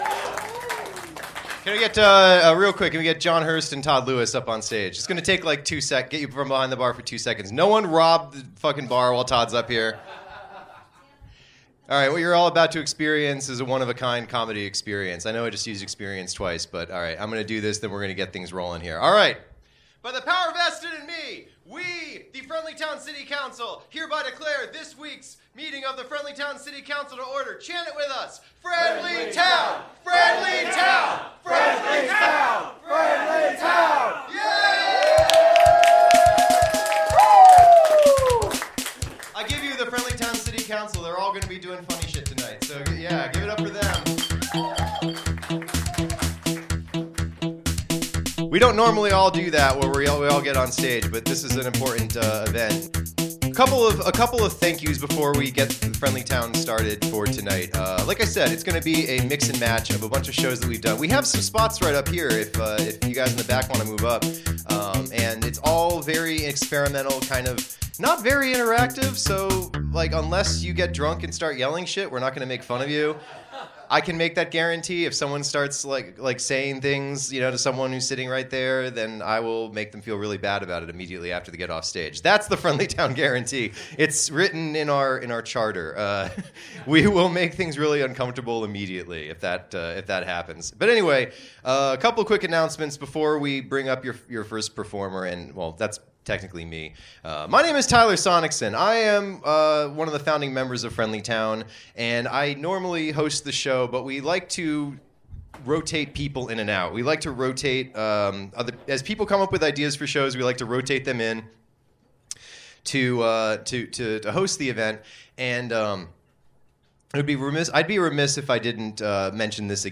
Recorded Live at the Pilot Light August 21, 2017, Knoxville TN